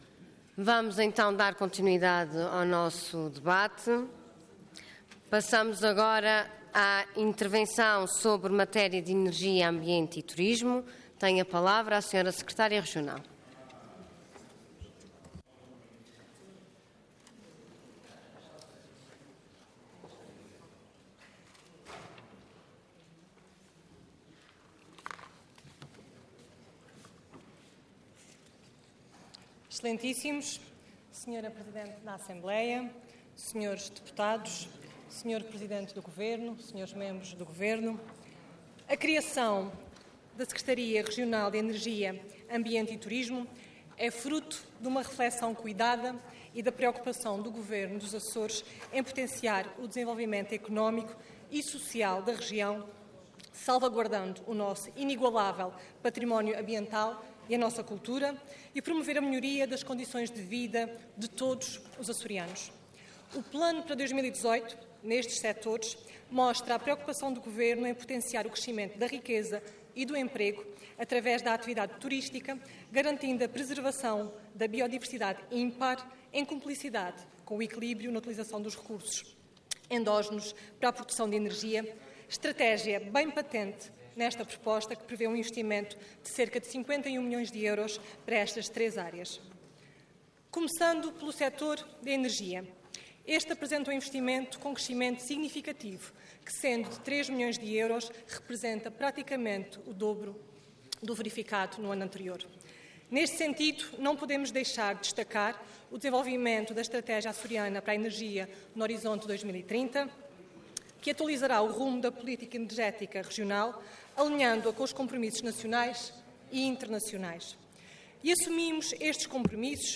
Detalhe de vídeo 28 de novembro de 2017 Download áudio Download vídeo Processo XI Legislatura Energia, Ambiente e Turismo - Apresentação e debate - Plano Anual Regional para 2018 e Orçamento da Região Autónoma dos Açores para 2018 Intervenção Proposta de Decreto Leg. Orador Marta Guerreiro Cargo Secretária Regional da Energia, Ambiente e Turismo Entidade Governo